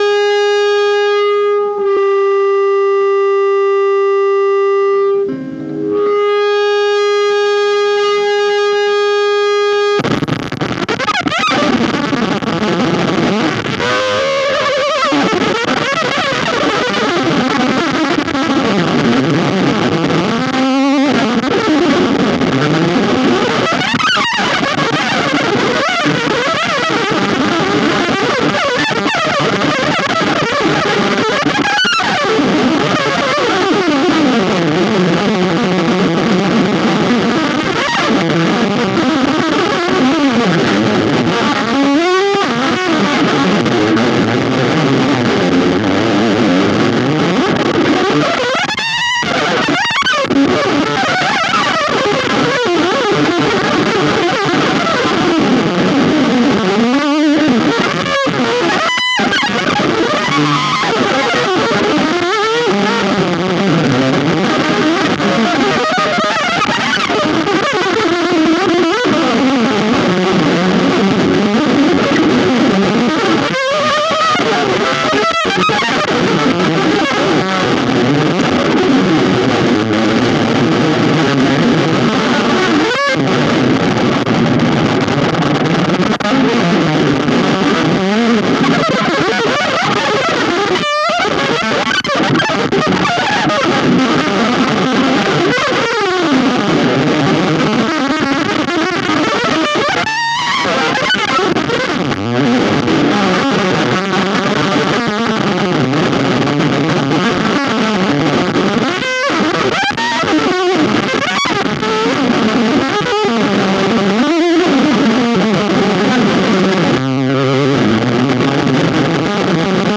シンプルでセクシーな無伴奏ギター独奏の、
スタジオ・ライブレコーディング・アルバムです。
前作とは違い、本作は全編、激しく美しい、豊潤なFuzz効果で満たされています。
それを大音量で鳴らし、マイク録音されました。